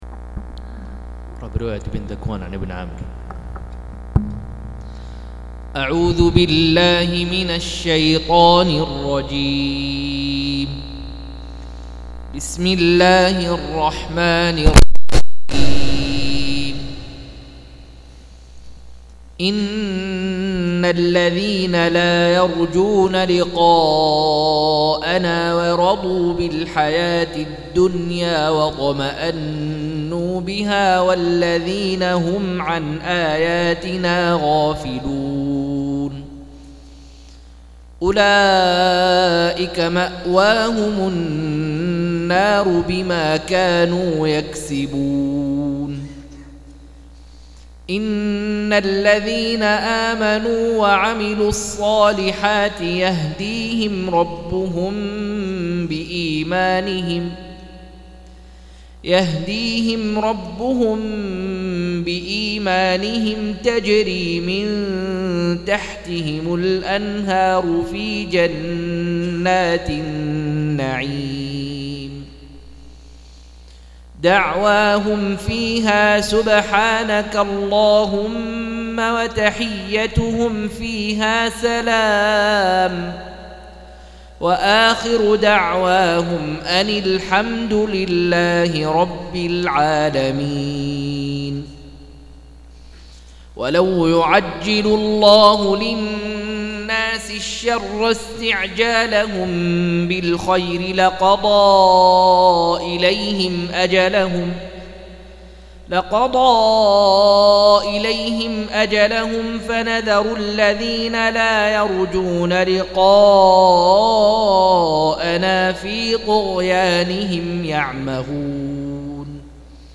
199- عمدة التفسير عن الحافظ ابن كثير رحمه الله للعلامة أحمد شاكر رحمه الله – قراءة وتعليق –